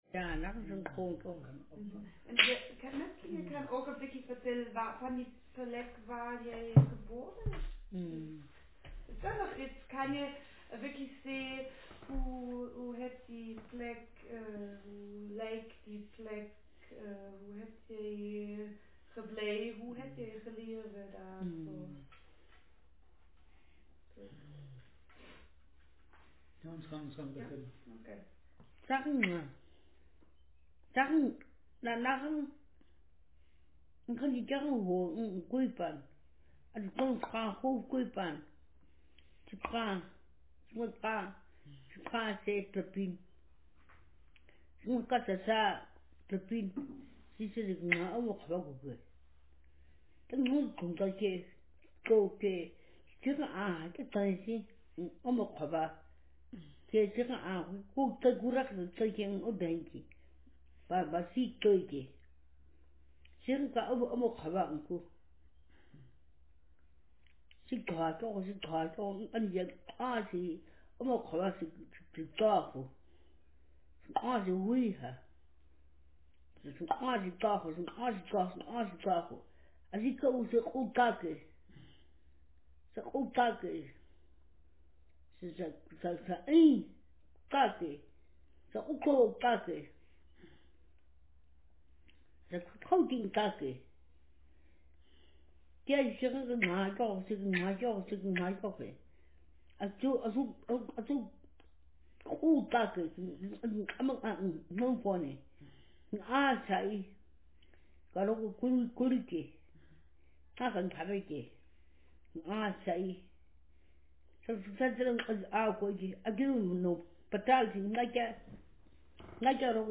Speaker sex f/f Text genre conversation